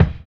LO FI 7 BD.wav